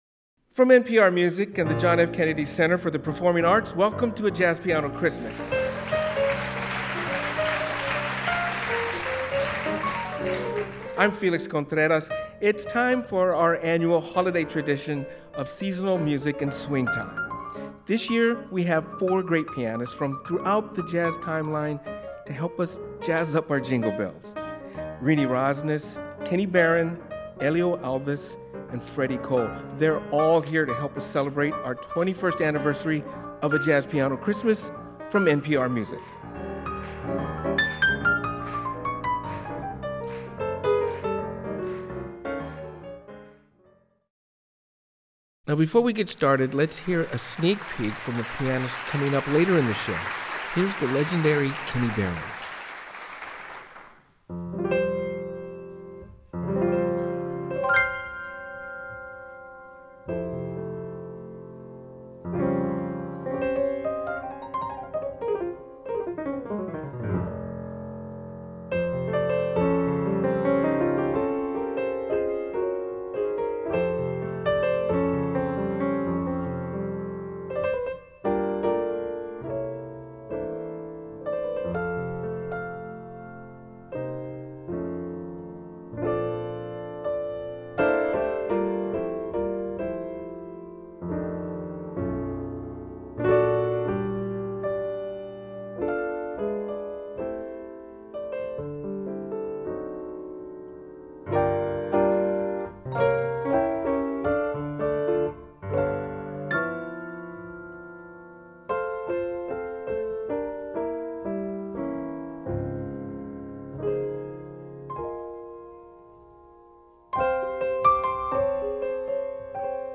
Live from the Kennedy Center in Washington, D.C.
annual celebration of seasonal music in swing time